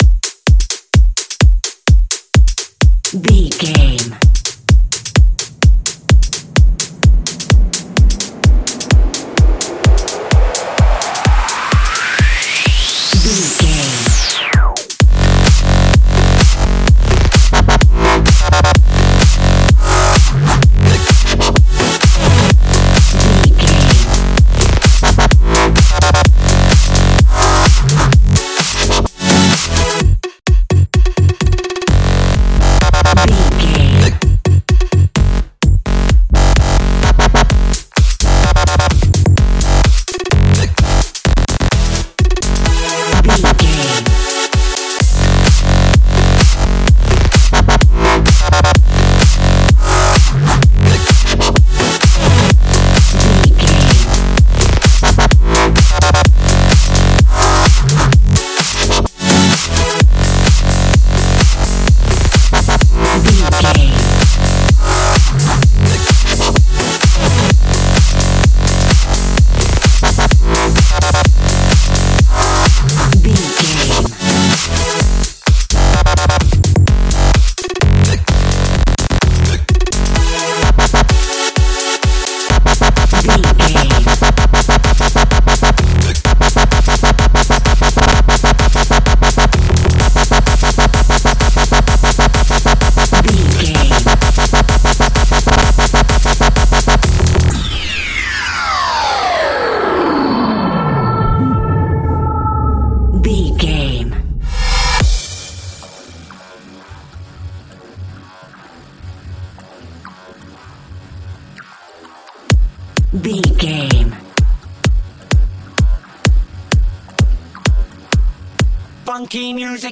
Dirty Modern Dubstep.
Epic / Action
Fast paced
Aeolian/Minor
F#
aggressive
powerful
dark
intense
synthesiser
drum machine
futuristic
breakbeat
energetic
synth leads
synth bass